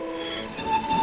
violin
Added violin